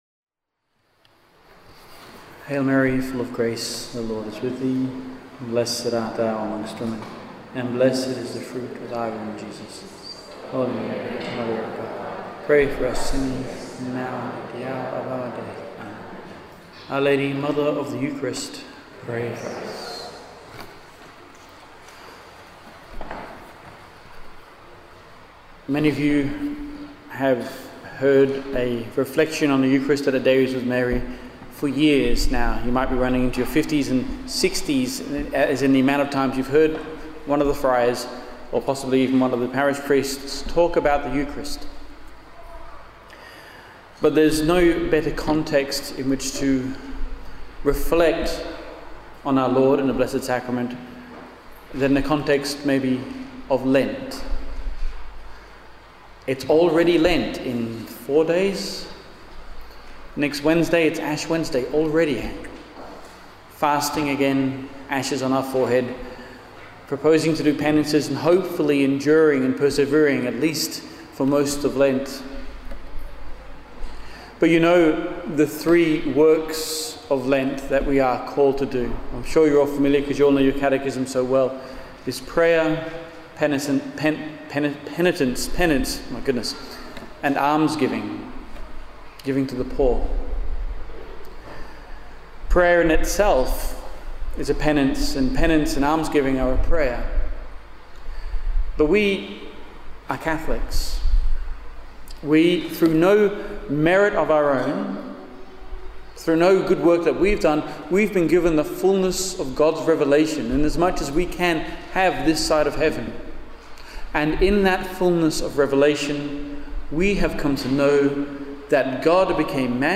held at Our Lady Queen of Martyrs Parish in Maylands, Western Australia on 6 February 2016.